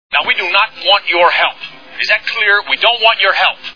Die Hard Movie Sound Bites